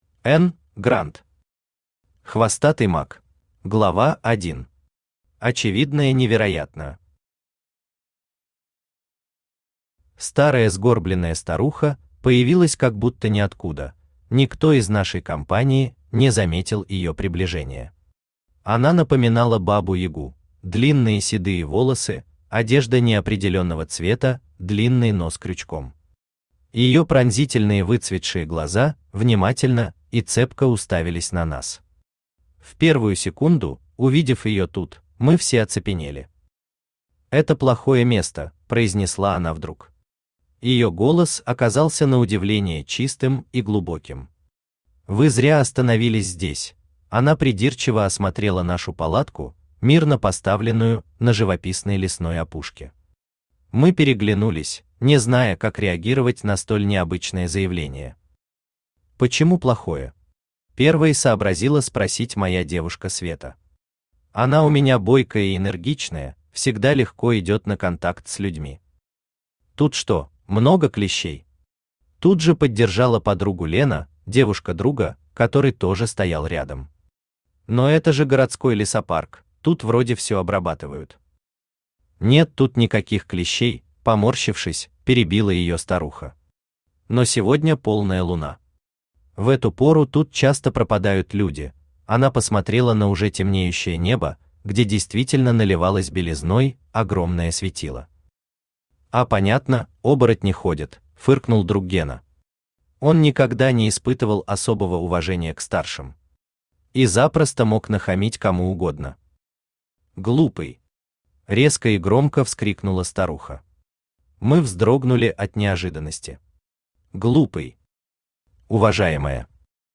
Аудиокнига Хвостатый маг | Библиотека аудиокниг
Aудиокнига Хвостатый маг Автор Н. Гранд Читает аудиокнигу Авточтец ЛитРес.